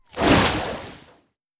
wateryzap1.wav